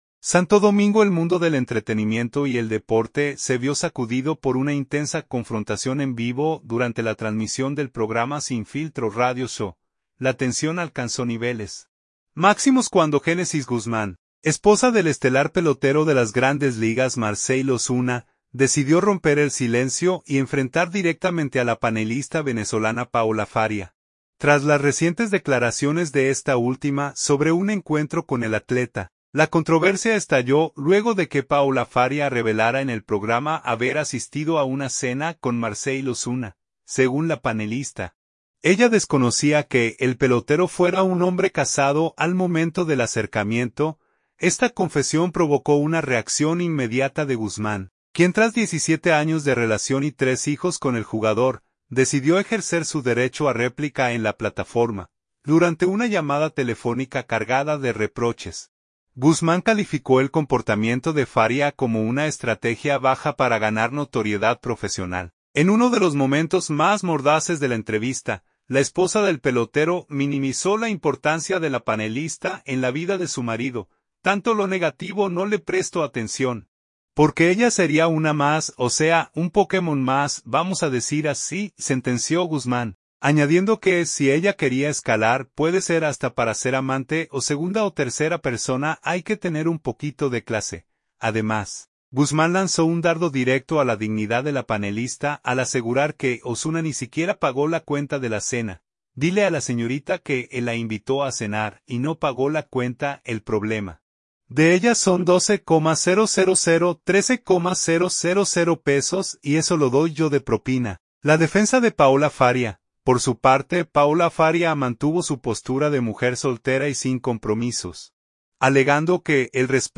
SANTO DOMINGO – El mundo del entretenimiento y el deporte se vio sacudido por una intensa confrontación en vivo durante la transmisión del programa Sin Filtro Radio Show.